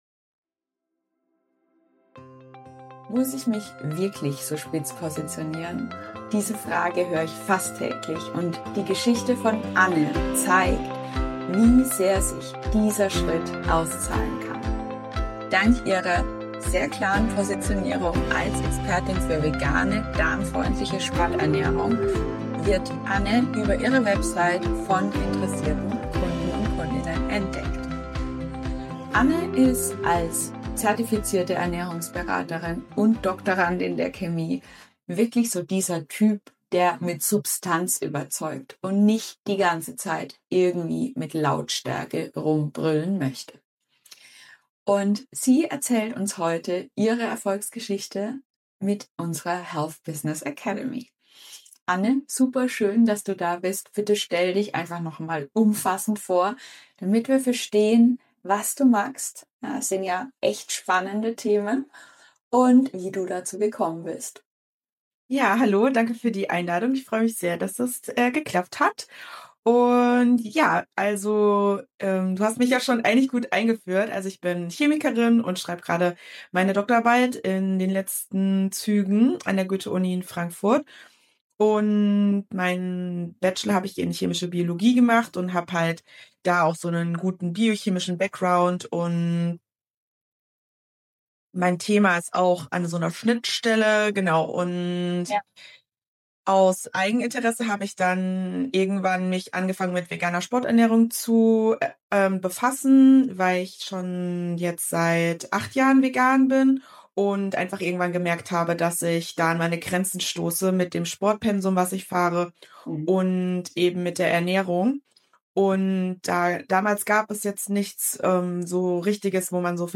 Wir sprechen im Interview darüber, wie wichtig fachliche Tiefe wieder ist wie wir